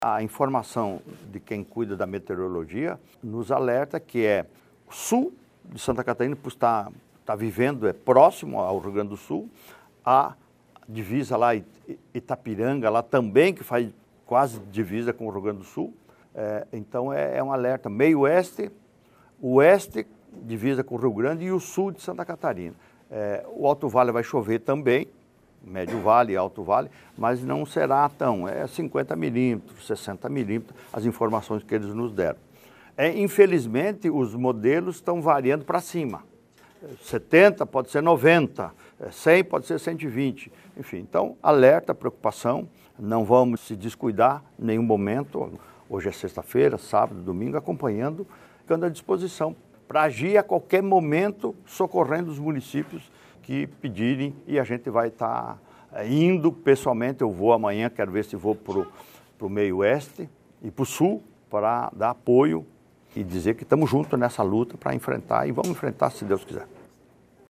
O governador Jorginho Mello acompanhou a situação da chuva em Santa Catarina, na manhã desta sexta-feira, 3, na sede da Secretaria de Estado da Proteção e Defesa Civil (SDC), em Florianópolis. Ele falou dos alertas e fez um pedido para a população não se descuidar:
SECOM-Sonora-governador-chuvas-em-SC.mp3